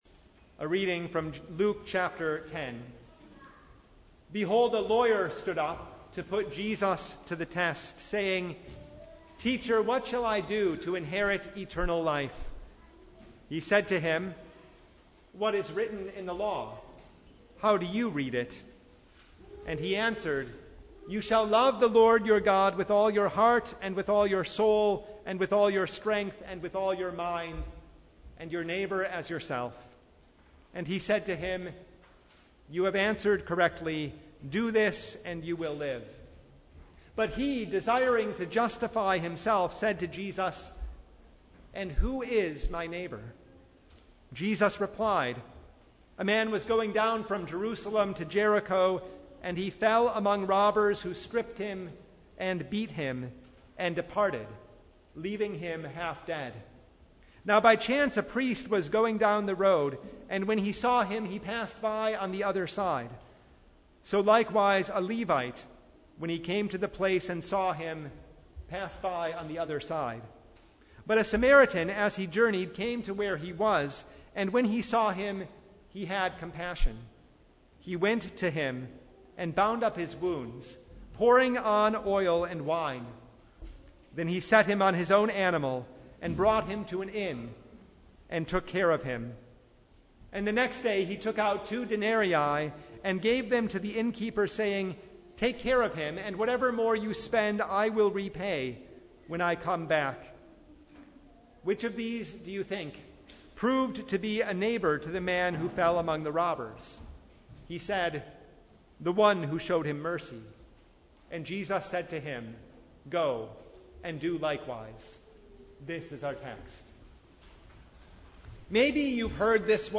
Sermon from Consecration Sunday (2022)